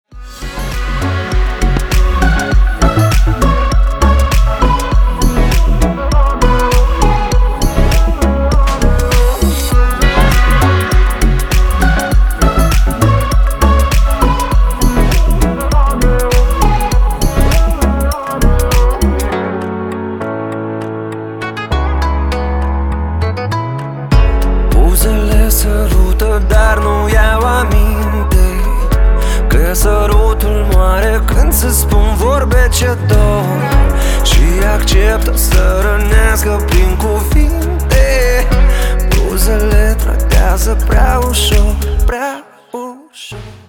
• Качество: 320, Stereo
Группа из Молдавии